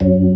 FST HMND F#2.wav